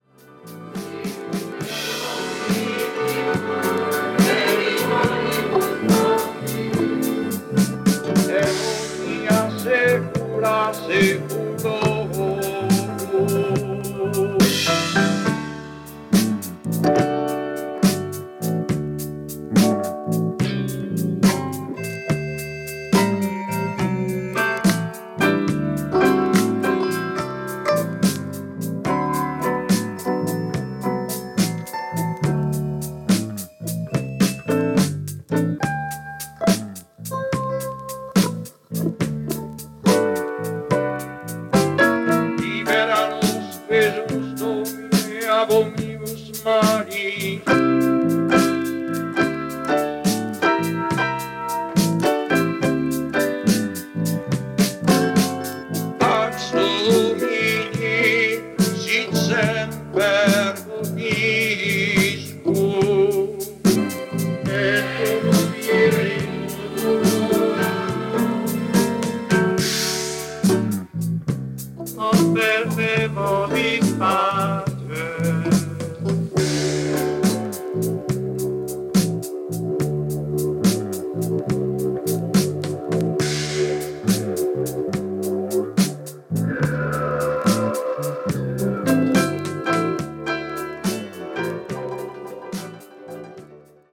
Bass
Percussion
Guitar